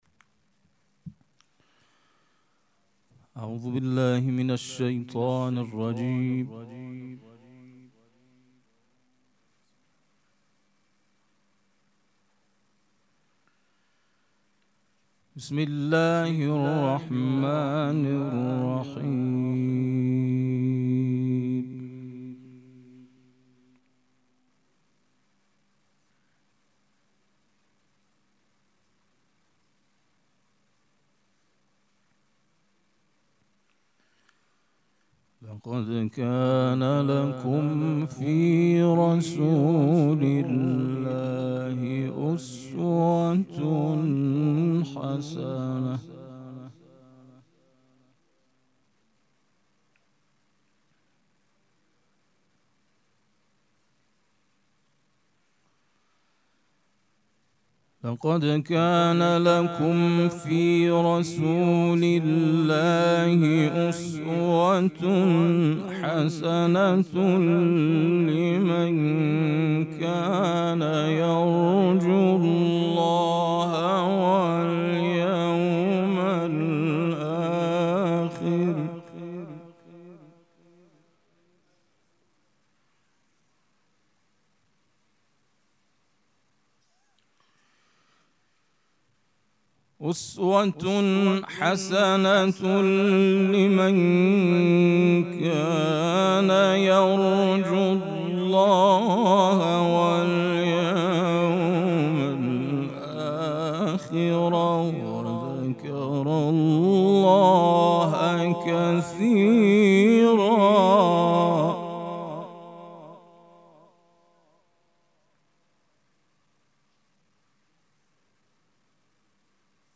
مراسم مناجات شب بیستم ماه مبارک رمضان
حسینیه ریحانه الحسین سلام الله علیها
قرائت قرآن